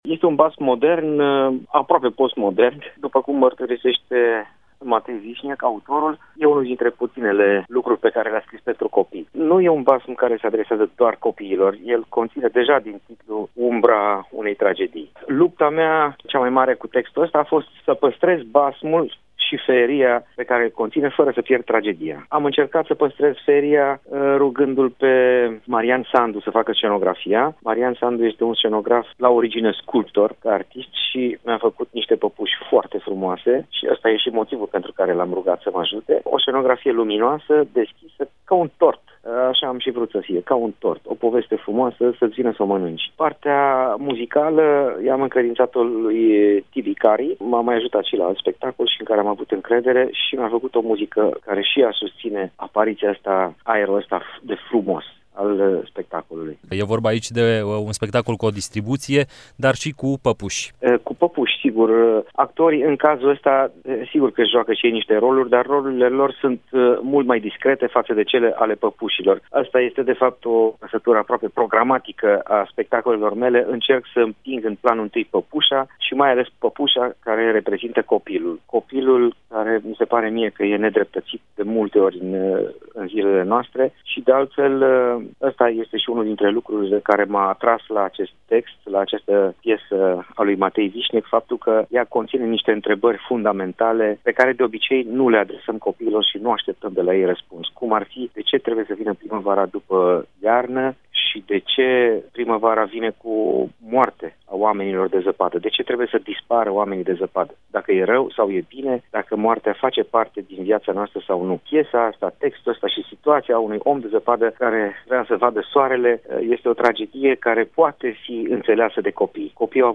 – interviu integral: